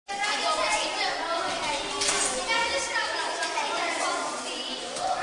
4 Zvuky z chodby.MP3